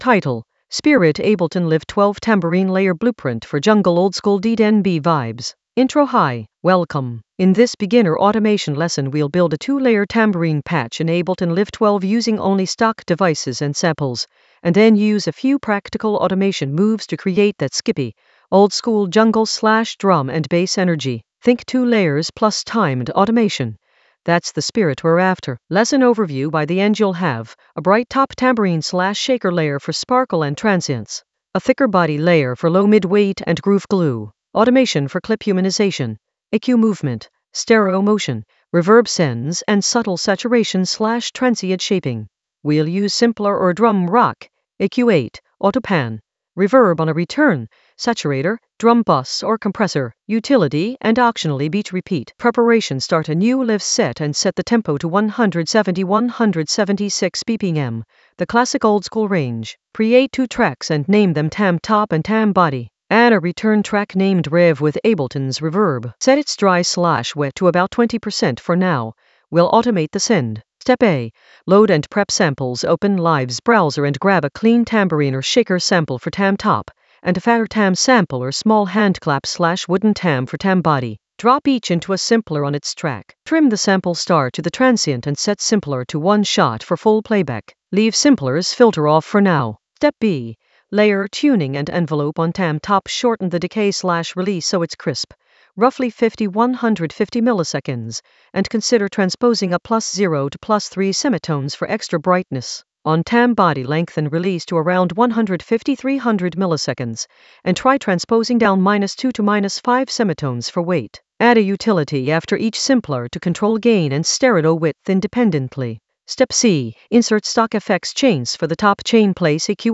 An AI-generated beginner Ableton lesson focused on Spirit Ableton Live 12 tambourine layer blueprint for jungle oldskool DnB vibes in the Automation area of drum and bass production.
Narrated lesson audio
The voice track includes the tutorial plus extra teacher commentary.